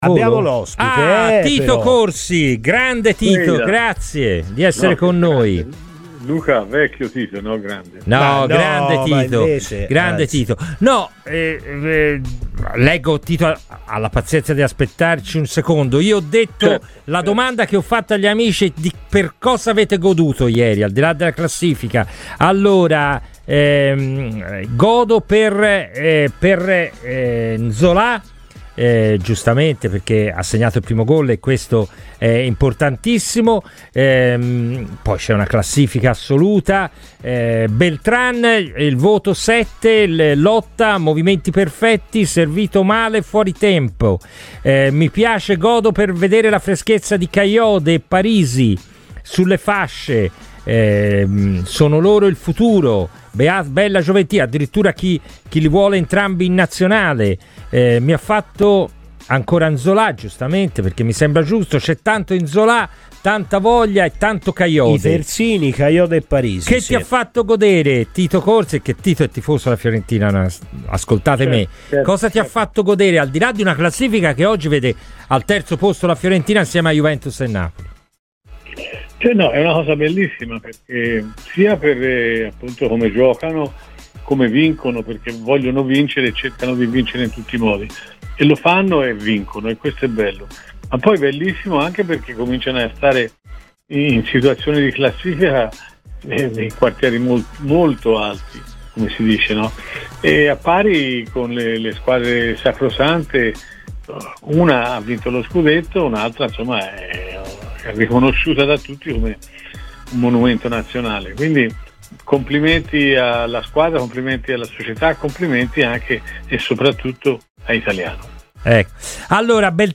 intervenuto durante la trasmissione " Palla al Centro" a cura della redazione di Radio Firenze Viola